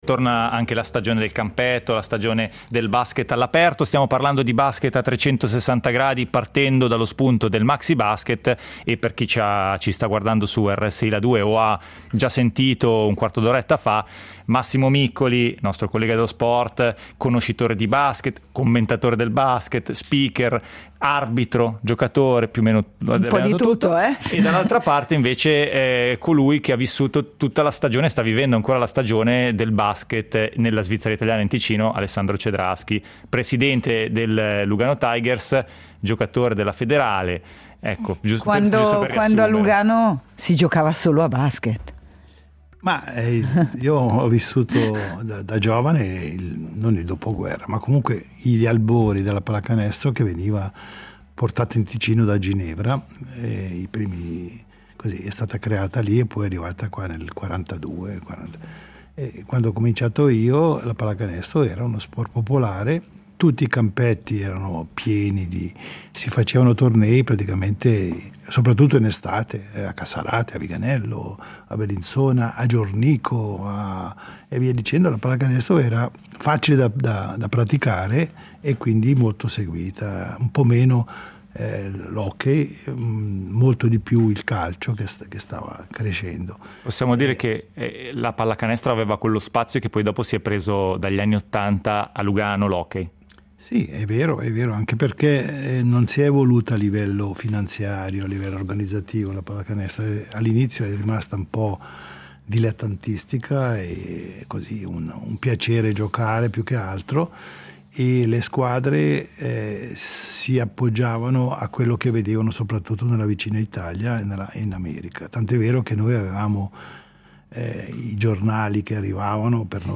Così per Sport ha dedicato la mattinata di Rete Uno allo sport della palla a spicchi.